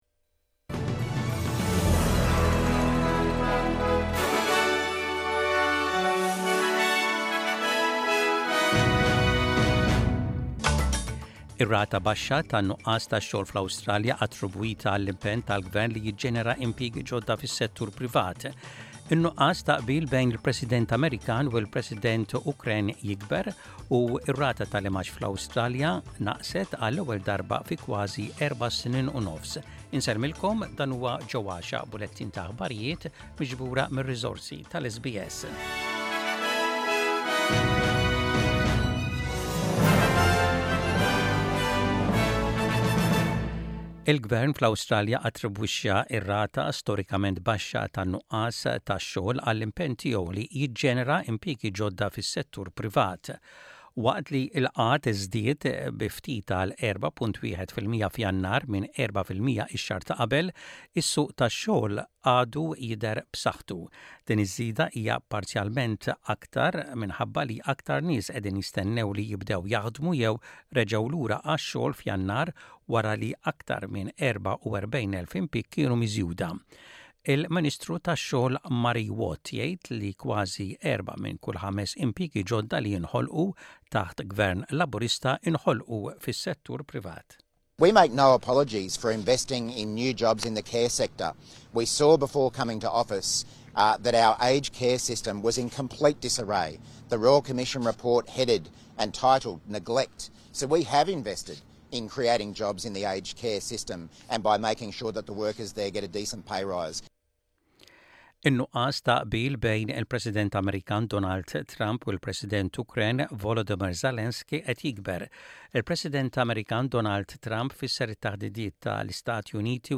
Aħbarijiet bil-Malti: 21.02.25